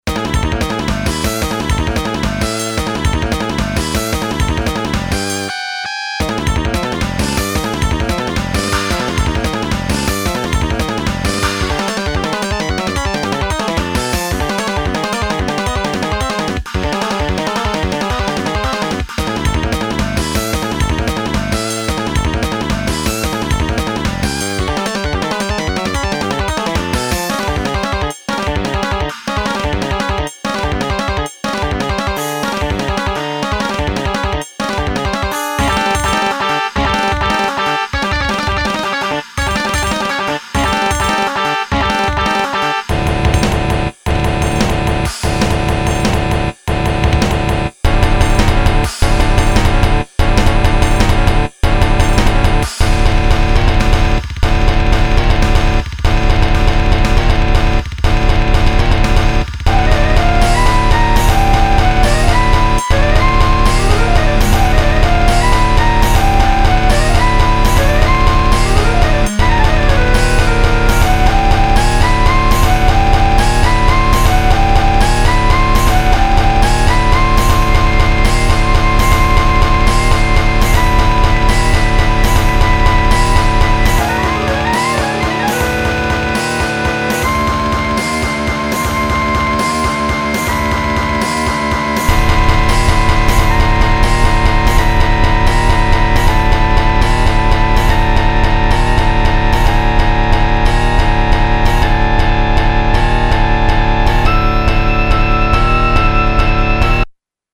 Ohmsägør, c'est une base metal ajoutant beaucoup d'éléments étrangers, du poprock au bebop en passant par le classique baroque, le death, le prog, la salsa.
La musique reste accessible mais particulièrement casse tête et technique, avec moults changements de tempo et harmonisations dangereusement...délicates, je dirais.
EDIT: J'ai rajouté une conversion midi en mp3, je sais que tout le monde n'a pas un midi génial donc ca donnera une idée un peu plus claire j'espère.